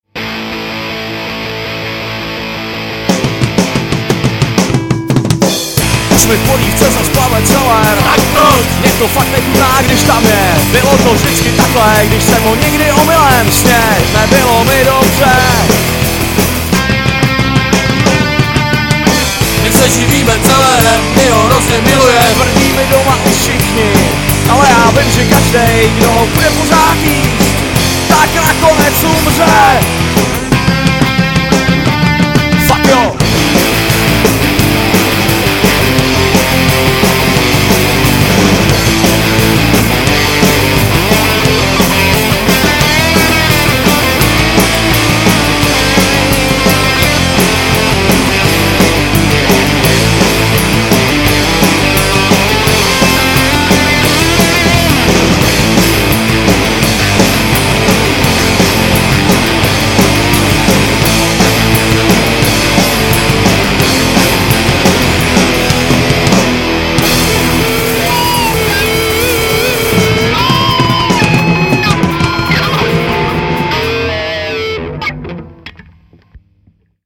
Žánr: Punk
punkrockové kapely